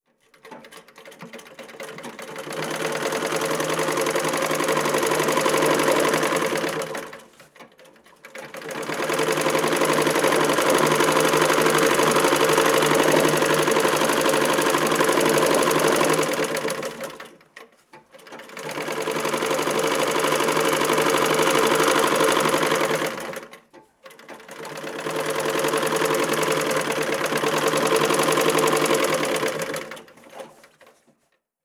Máquina de coser antigua 2
máquina de coser
Sonidos: Industria
Sonidos: Hogar